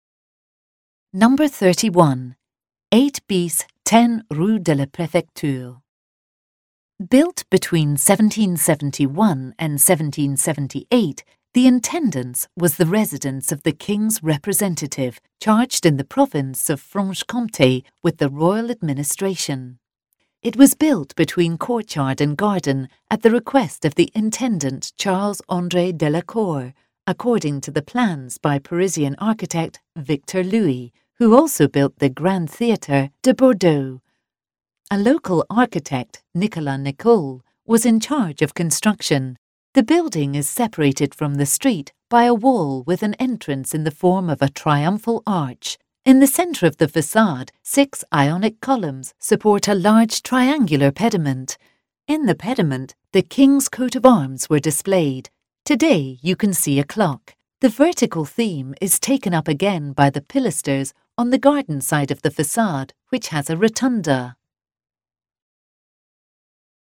Ecouter l'audio guide